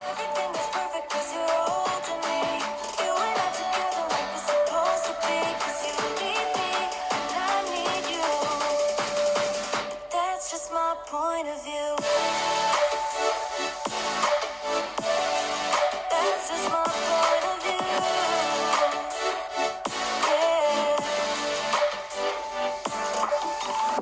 Xperia 1 IVは、フルステージステレオスピーカーを搭載。
▼Xperia 1 IVのフルステージステレオスピーカーの音はこちら！
実際に様々な"音"をXperia 1 IVから出して検証してみましたが、クリアで臨場感ある音に加え、左右の音のバランスも均一で聴こえ方も素晴らしいです。
従来比で中低音がしっかりした印象で、全体的にグッと迫力が増していて、映画っぽいエモいサウンドを楽しめるスピーカーに仕上がっています。
Xperia1IV-Speaker.m4a